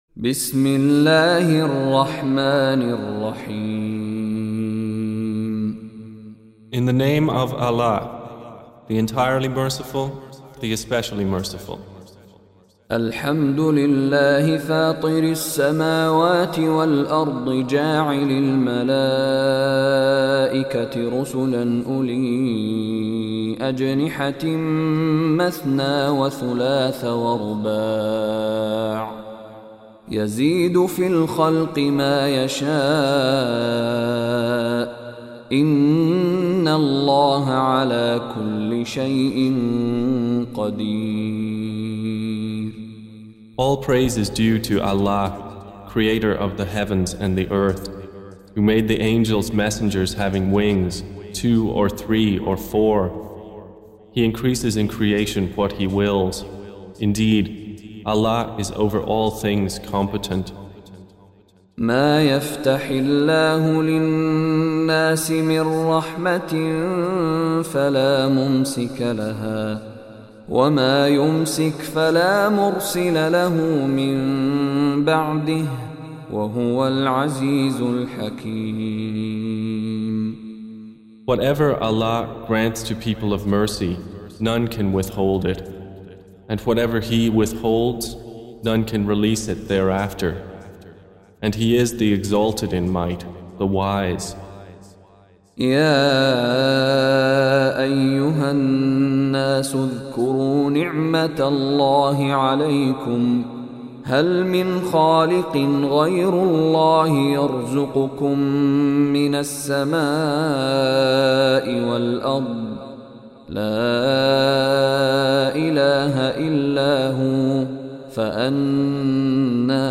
Surah Repeating تكرار السورة Download Surah حمّل السورة Reciting Mutarjamah Translation Audio for 35. Surah F�tir or Al�Mal�'ikah سورة فاطر N.B *Surah Includes Al-Basmalah Reciters Sequents تتابع التلاوات Reciters Repeats تكرار التلاوات